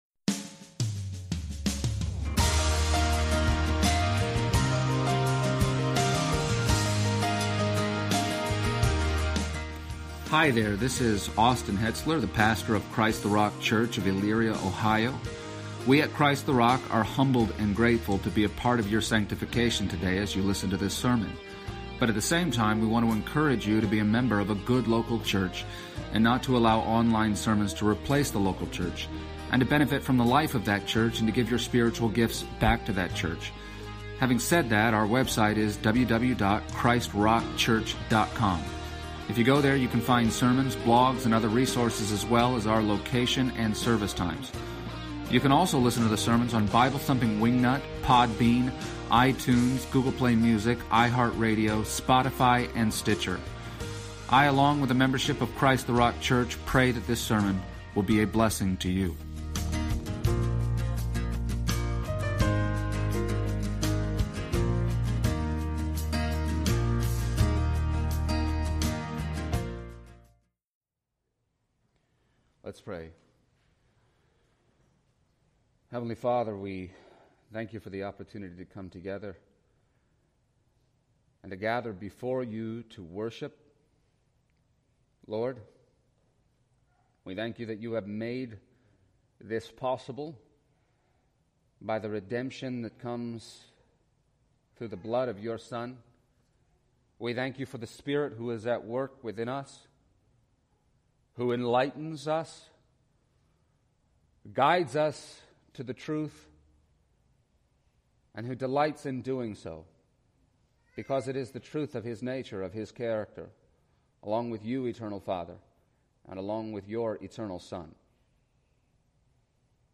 Series: Sunday Service
Psalm 144:1-15 Service Type: Sunday Morning %todo_render% « Man of God